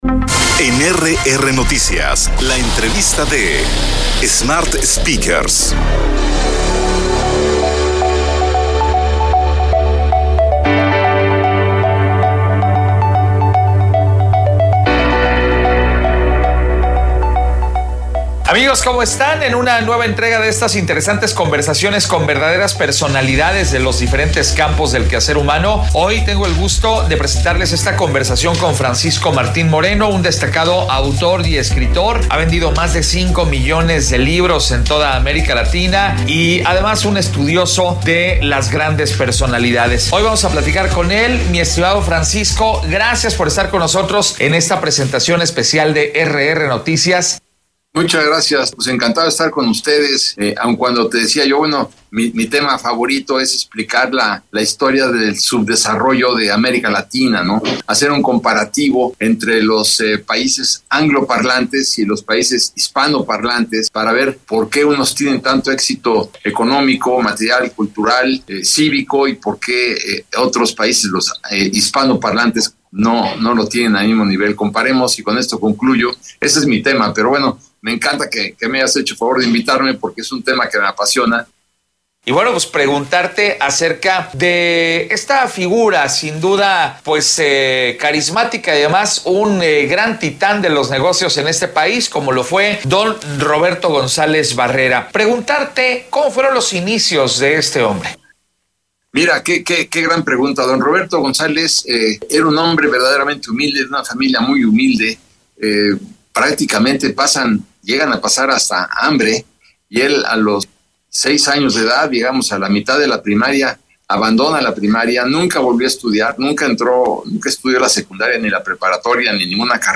Entrevistas Podcast Francisco Martín Moreno| ¿Qué ha caracterizado a grandes líderes mexicanos?